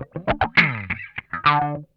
OUCH SWOOP.wav